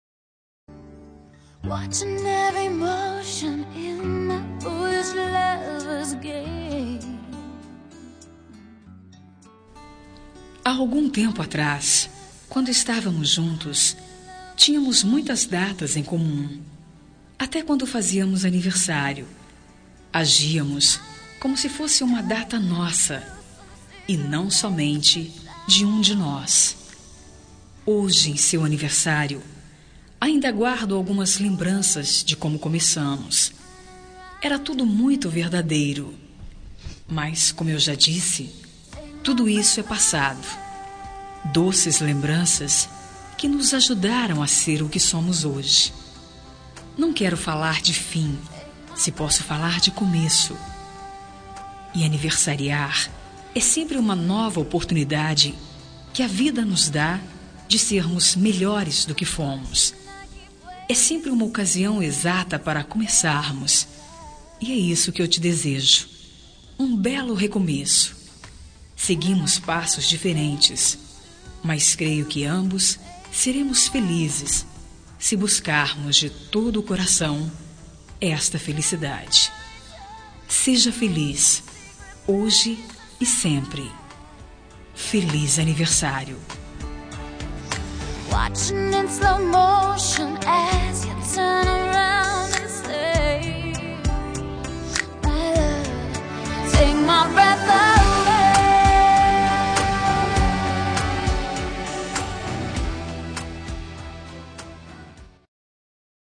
Telemensagem de Aniversário de Ex. – Voz Feminina – Cód: 1354